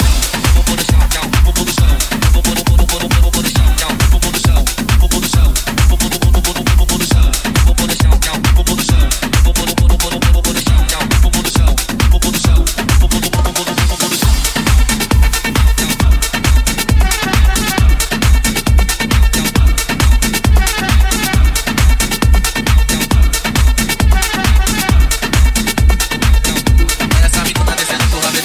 tech house hits
Genere: house,tec house,tecno,remix,hit